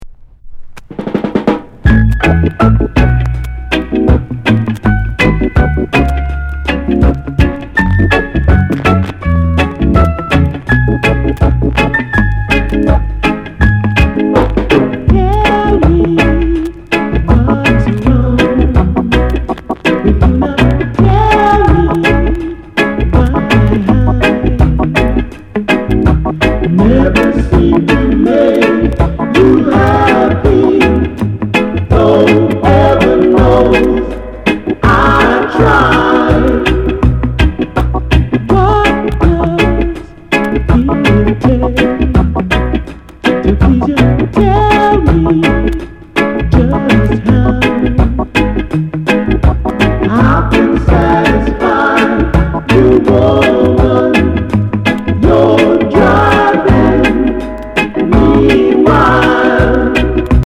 NICE SOUL COVER